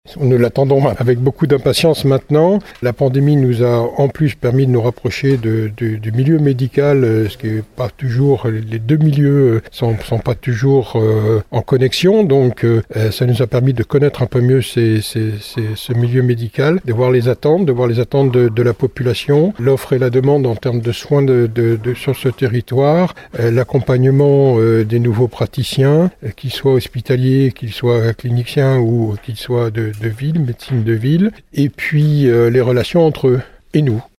On écoute le président Vincent Barraud :